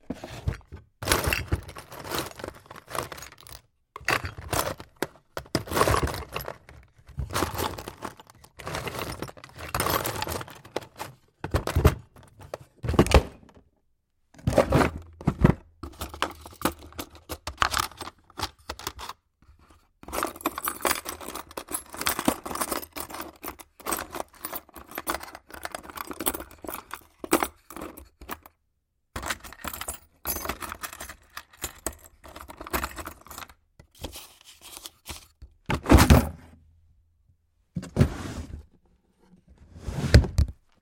随机的 " 抽屉木头打开关闭寻找钥匙的杂物 拨浪鼓2
描述：抽屉木头打开密切搜索通过垃圾密钥rattle2.wav